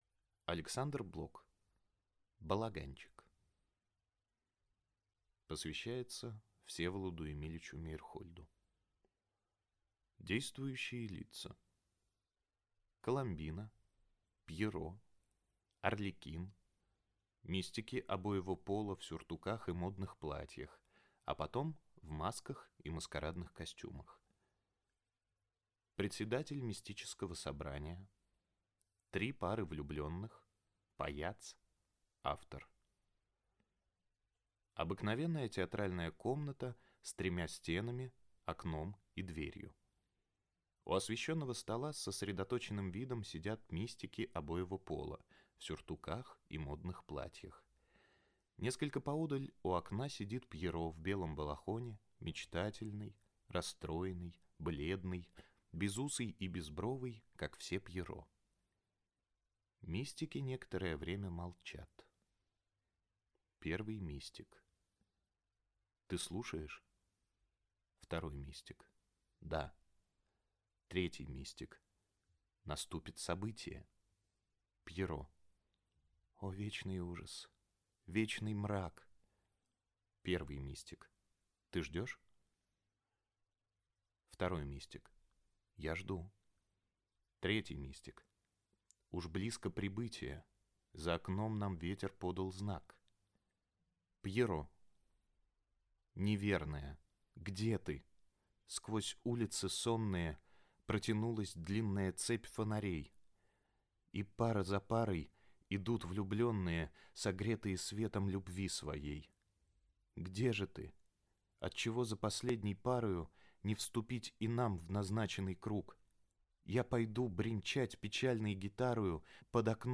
Аудиокнига Балаганчик | Библиотека аудиокниг